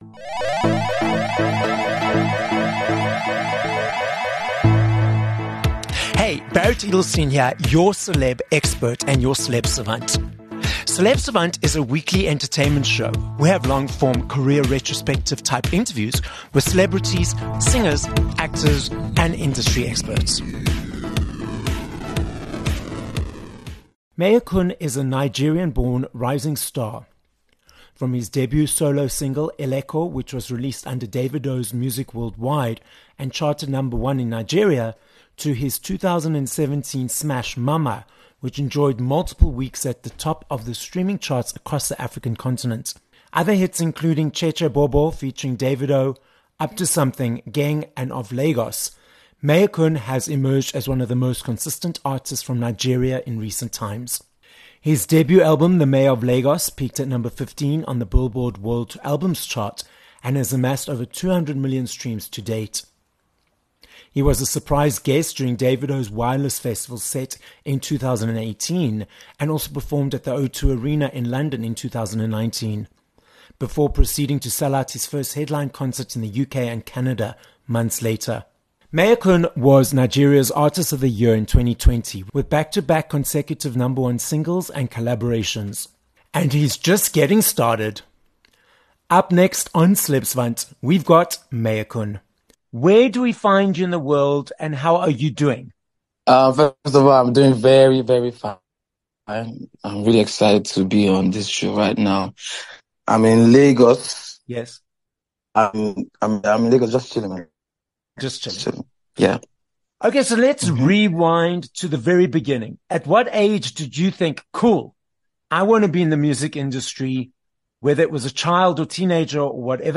23 Dec Interview with Mayorkun